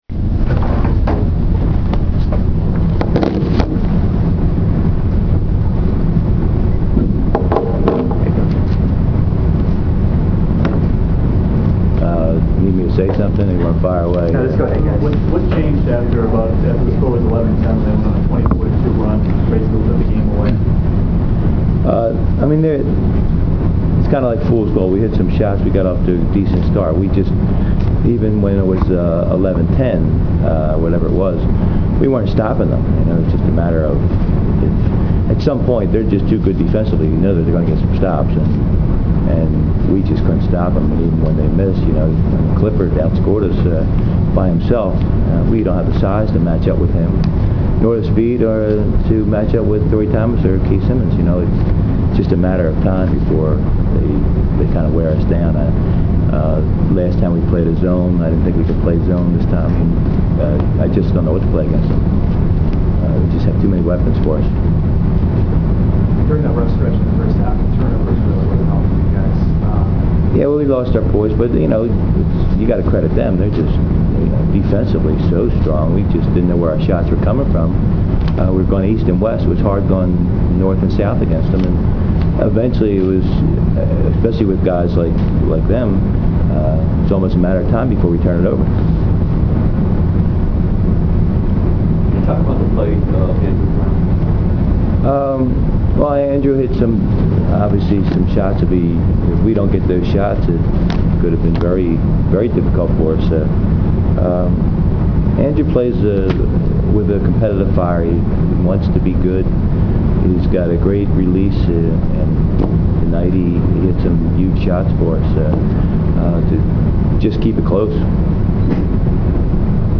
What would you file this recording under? Postgame audio: